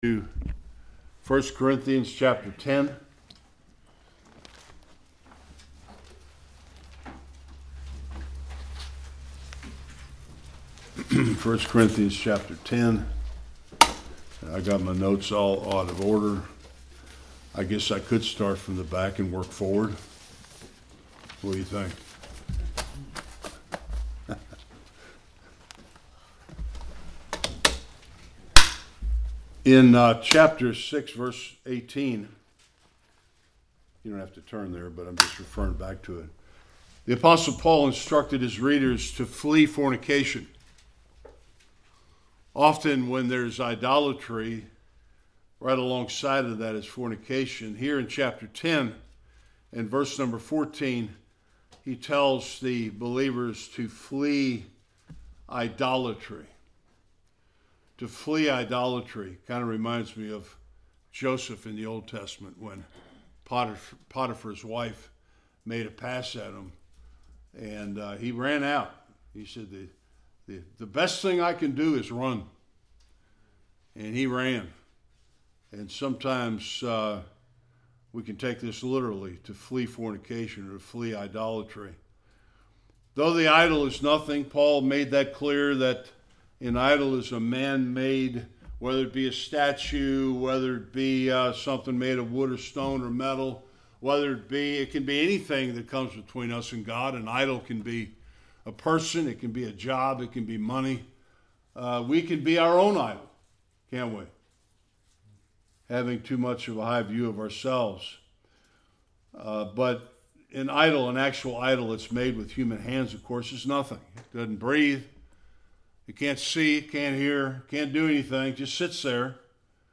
14-33 Service Type: Sunday Worship Paul gives more instructions regarding Christian freedom and responsibility.